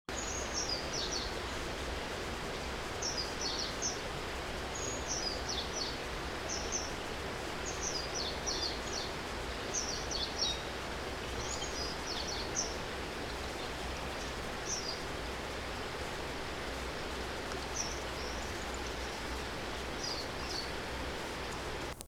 Jilguero europeo (Carduelis carduelis)